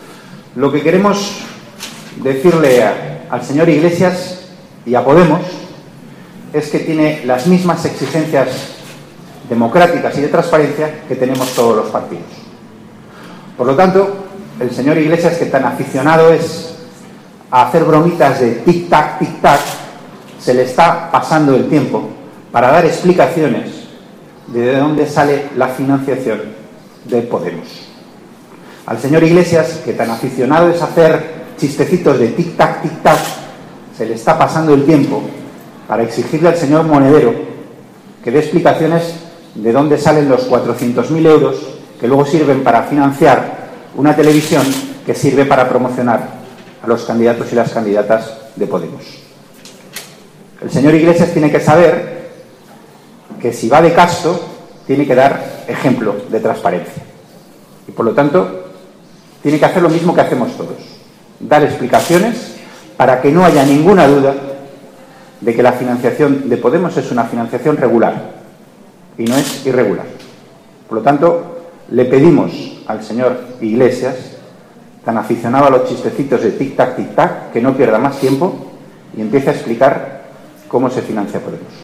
Declaraciones de Antonio Hernando sobre las últimas informaciones que afectan a Podemos y sus fuentes de financiación 28/1/2015